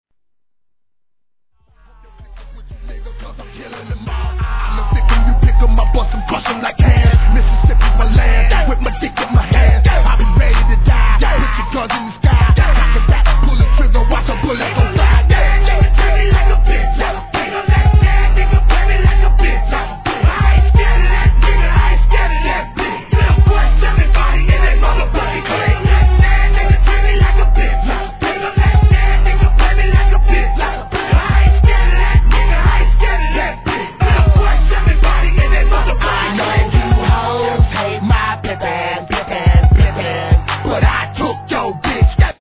1. HIP HOP/R&B
コンピュータが故障したような「ピロリロピロリロ」酔っ払った上音が鳴る中毒系バウンスにこの客演ですから。。